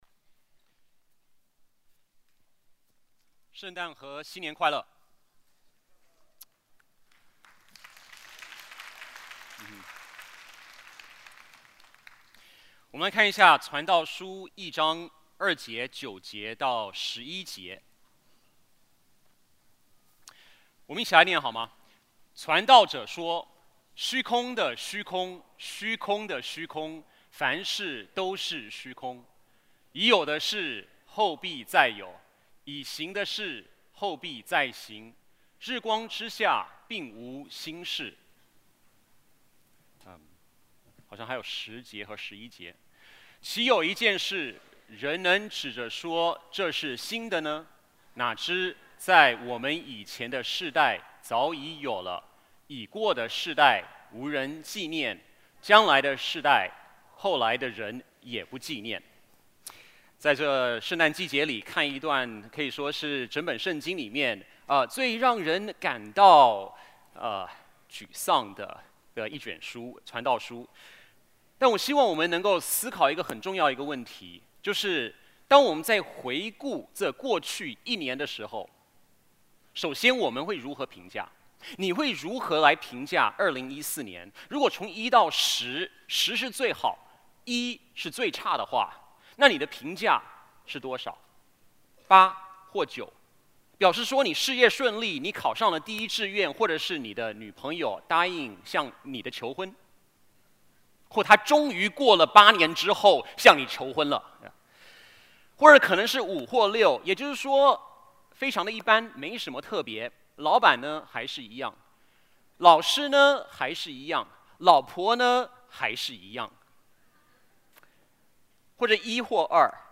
主日证道 |  圣诞信息（三）又过了一年